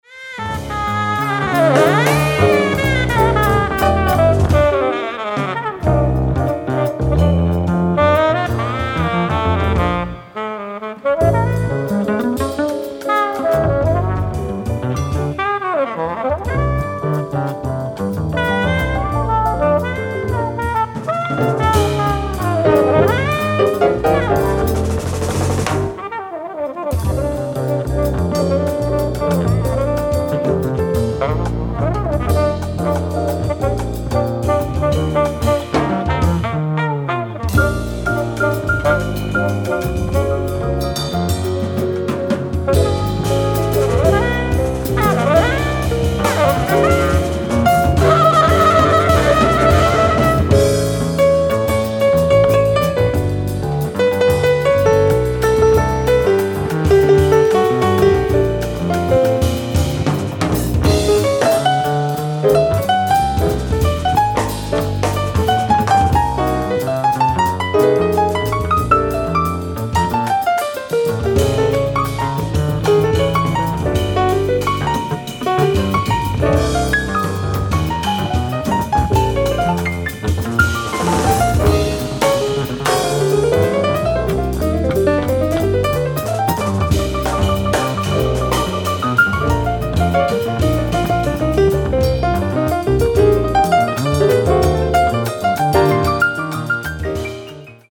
ライブ・アット・ジャズフェスト、ベルリン、ドイツ 11/06/1982
※試聴用に実際より音質を落としています。